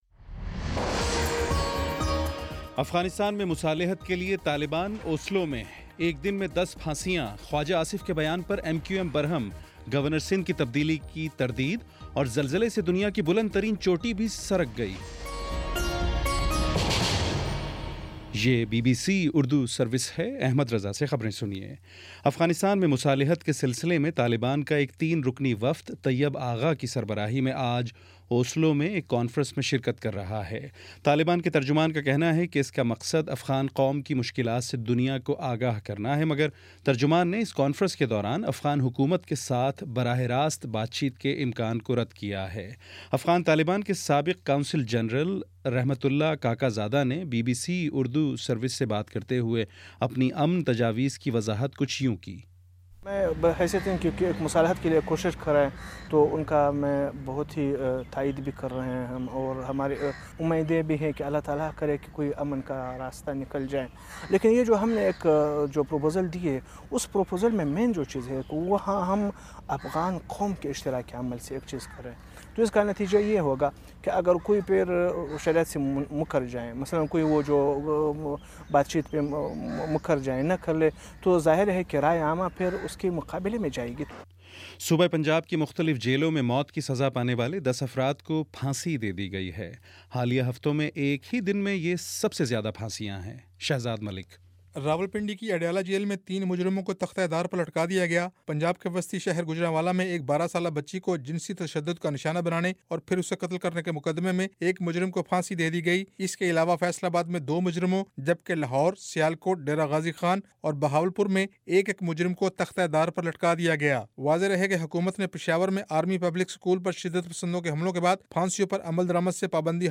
جون 16: شام پانچ بجے کا نیوز بُلیٹن